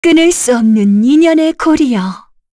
Yuria-Vox_Skill6_kr.wav